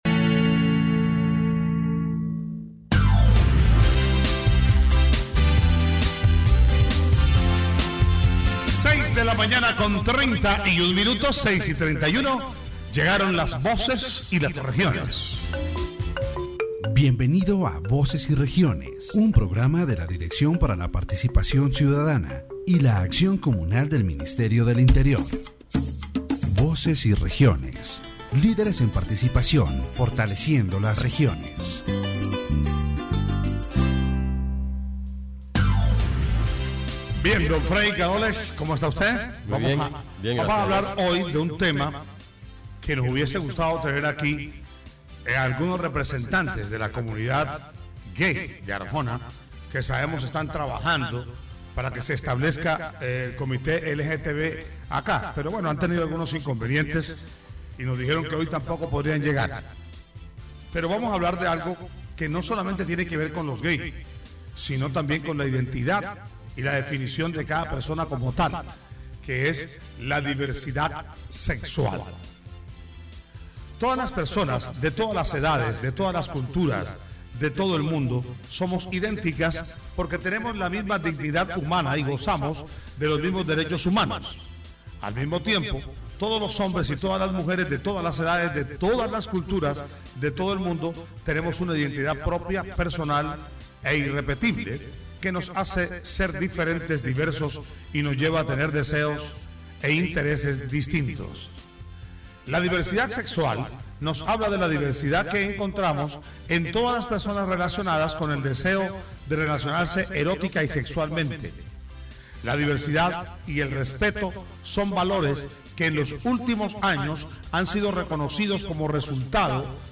The interview analyzes the legal framework of sexual diversity in Colombia and the public policies implemented to guarantee the rights of the community.